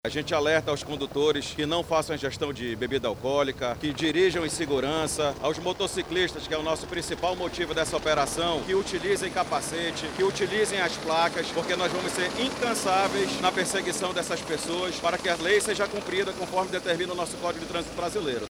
O diretor-presidente do Detran Amazonas, Wendell Waughan, reforça as orientações para que as leis de trânsito sejam respeitadas.